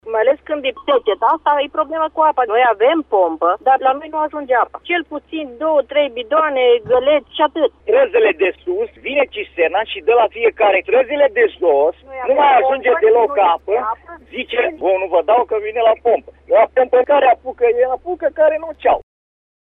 voci-oameni-Doman-HAR.mp3